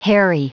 Prononciation du mot hairy en anglais (fichier audio)
hairy.wav